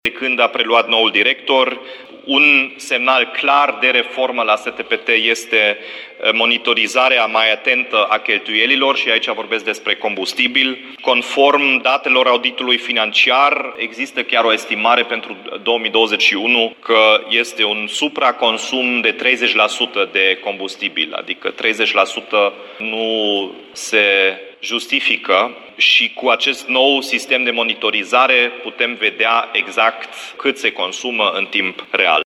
Ca urmare, monitorizarea cheltuielilor este una dintre preocupările importante  ale noii conducerii a STPT, spune primarul Dominic Fritz.